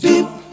Hip Vcl Kord-F.wav